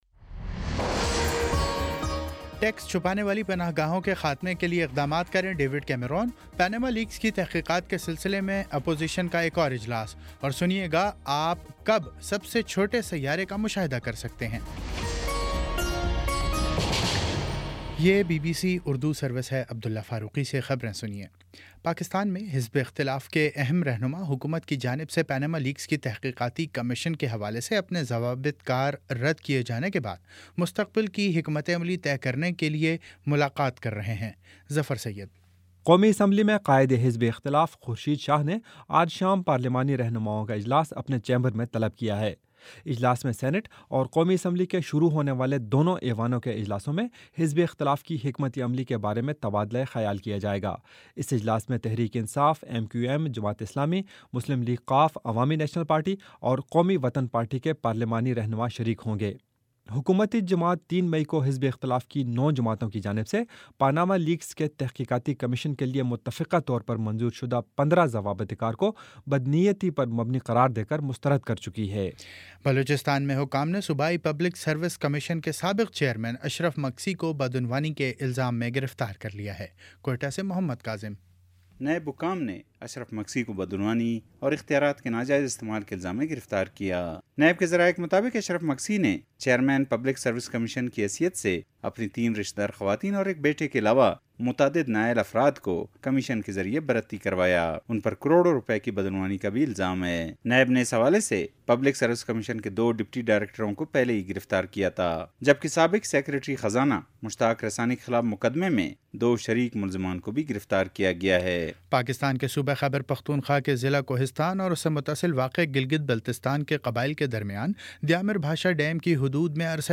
مئی 09 : شام پانچ بجے کا نیوز بُلیٹن